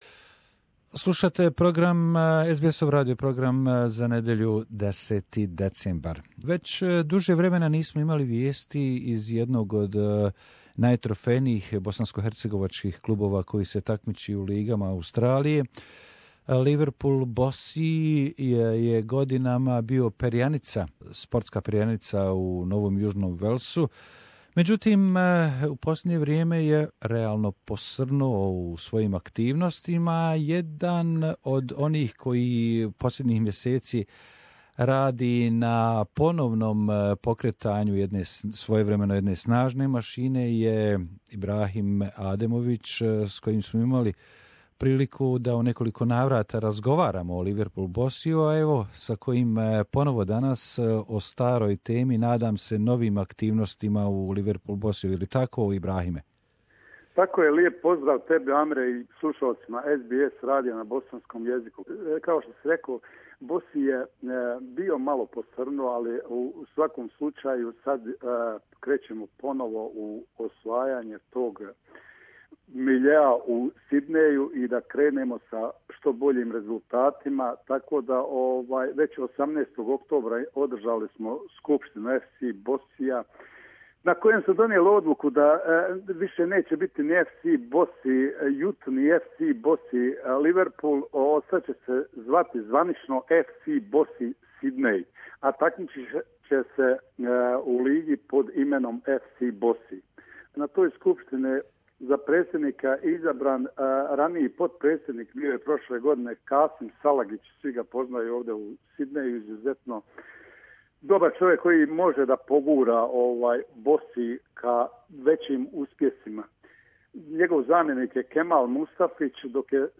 is talking to our radio program about new Bossy's activities and team participation in two soccer tournaments - Sydney and Melboune